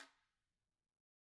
Guiro-Hit_v1_rr1_Sum.wav